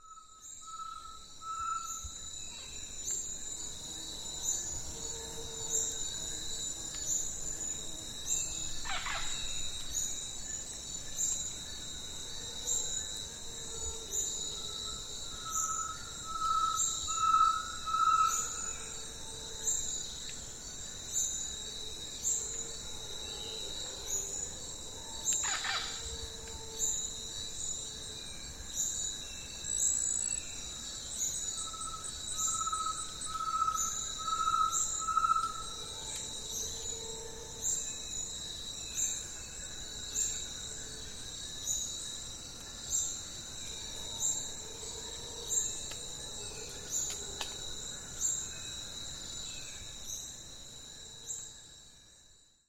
The Tinamou’s Mysterious Song
There’s nothing like a singing tinamou to remind you that you are in a tropical rainforest; the crepuscular (love that word!) song is a quavering paean to love and the forest itself.
I recorded a great tinamou this morning at dawn while strolling down a BCI path, through tangles of vines and understory beneath tall canopy giants in which a troupe of white-faced capuchins roved and leaped from branch to branch.
It’s an Olympus with stereo mics and only about the size of a cell phone. Solid state so no motor sounds or tape hiss.
greattinamou.mp3